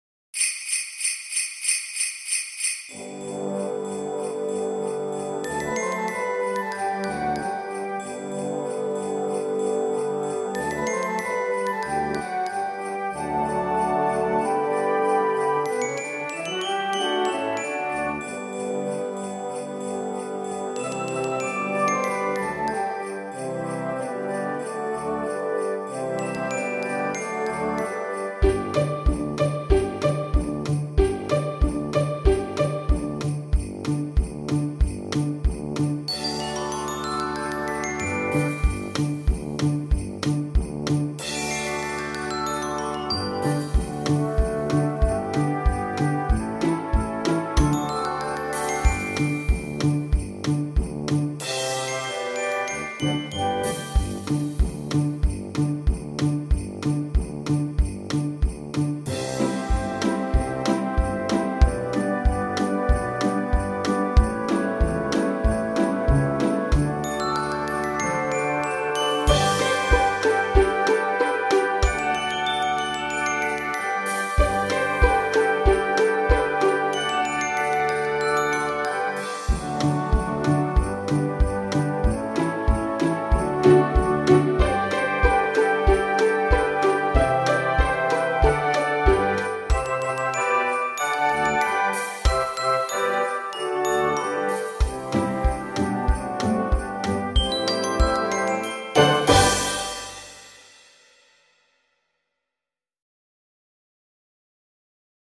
(accompaniment)